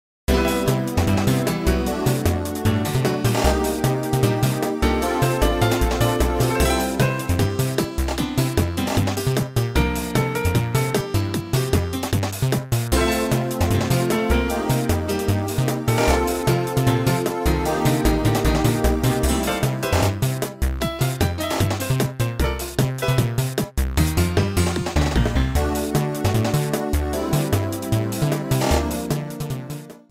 Cropped to approximately 30 seconds, applied fade-out